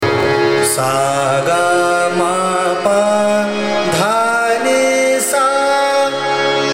The raga is characterized by its sweet and serene nature, evoking a sense of tranquility and beauty.
ArohaS G m P D N S’
Khammaj (Aroha)